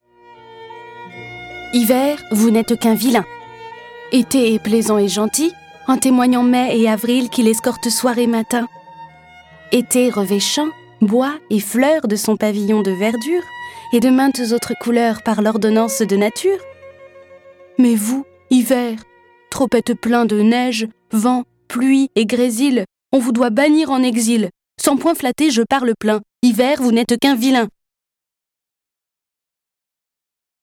Le récit et les dialogues sont illustrés avec les musiques de Beethoven, Borodine, Chopin, Corelli, Debussy, Dvorak, Grieg, Mozart, Pergolèse, Rimsky-Korsakov, Schubert, Tchaïkovski, Telemann et Vivaldi.